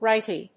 /ˈraɪti(米国英語), ˈraɪti:(英国英語)/